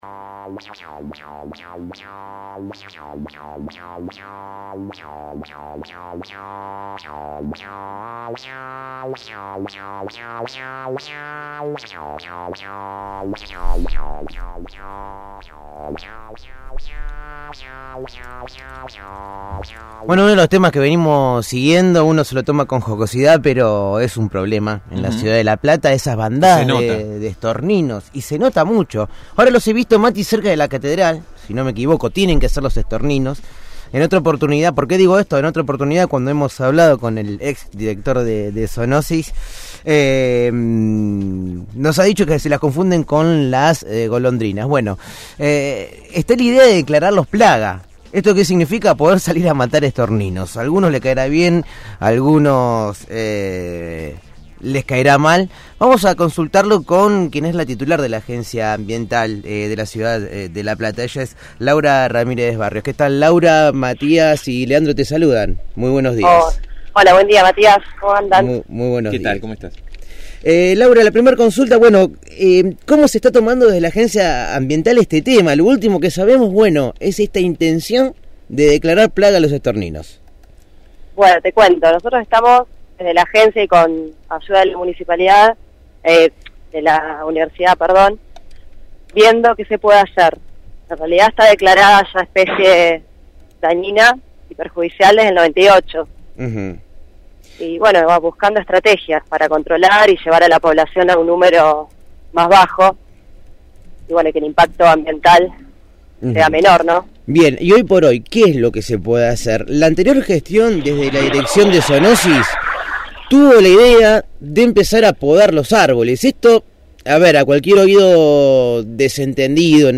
Laura Ramírez, Directora de la Agencia Ambiental de la Municipalidad de La Plata, dialogó